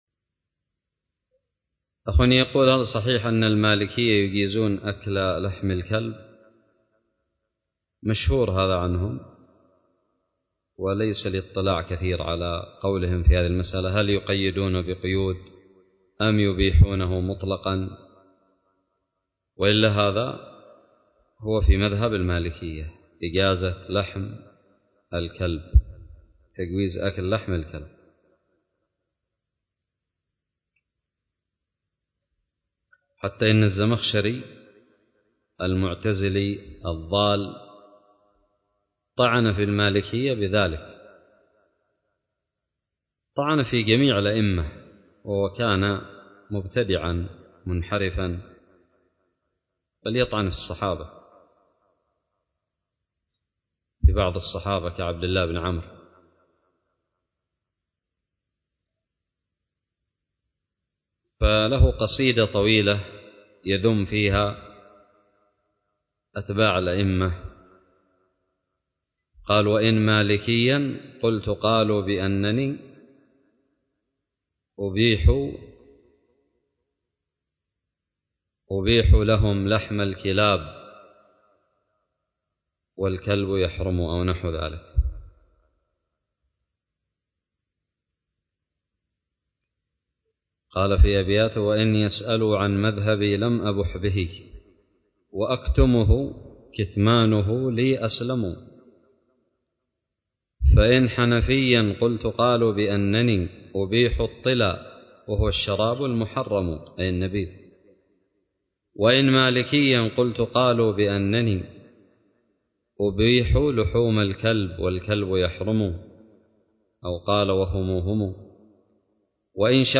:العنوان فتاوى عامة :التصنيف 1444-12-12 :تاريخ النشر 21 :عدد الزيارات البحث المؤلفات المقالات الفوائد الصوتيات الفتاوى الدروس الرئيسية هل صحيح أن المالكية يجيزون أكل لحم الكلب ؟ سؤال قدم لفضيلة الشيخ حفظه الله